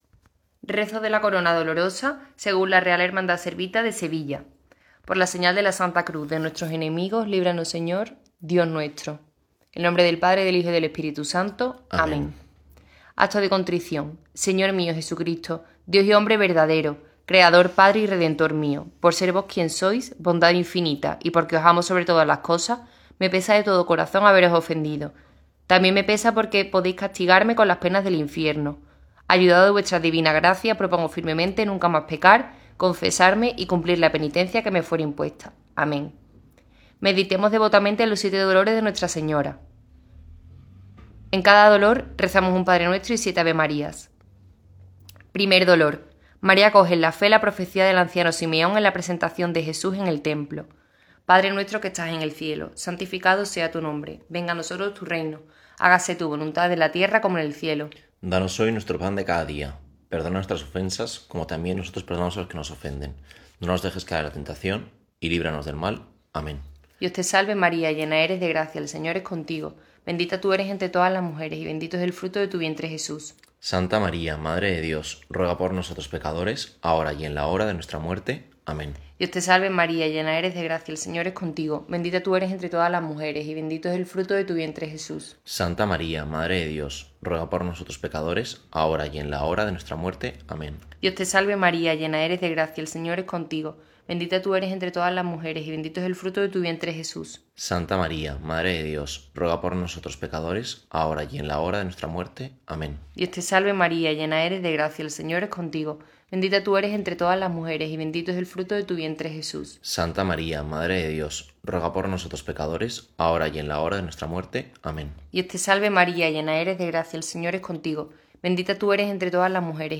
rezo-corona-dolorosa.mp3